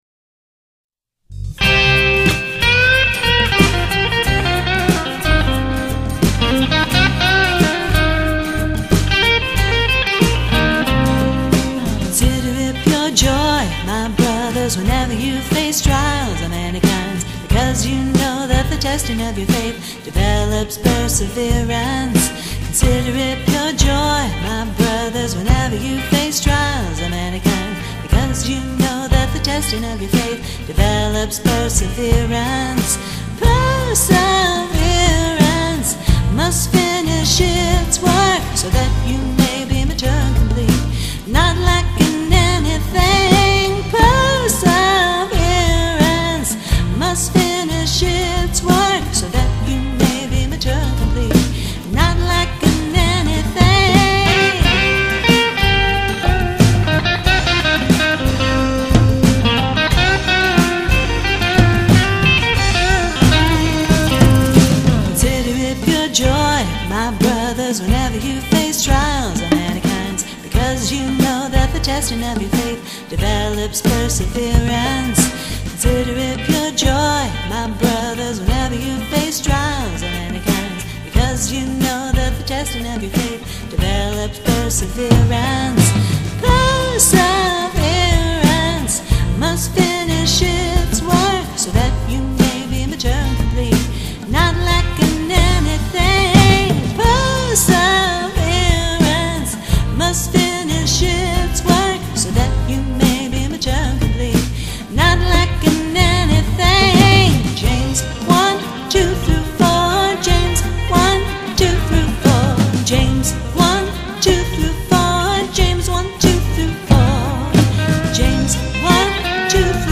With Vocals